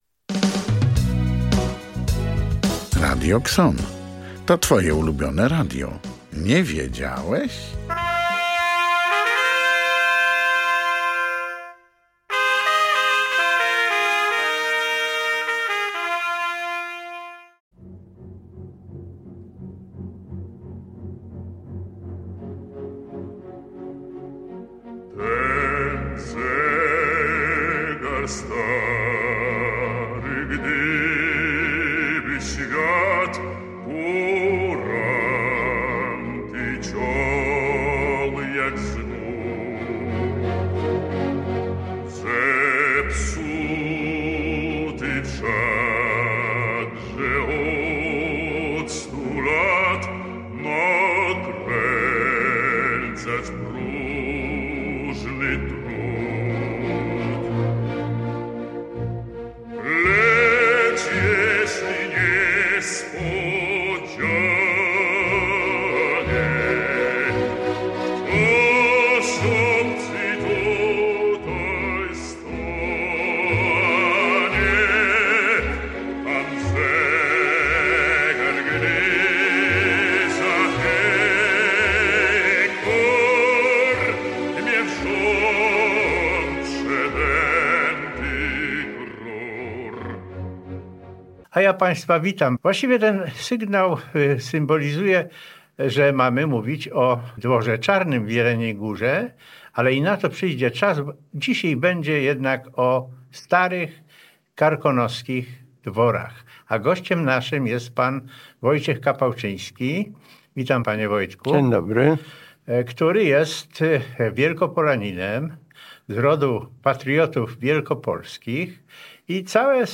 Dzisiejsza audycja to rozmowa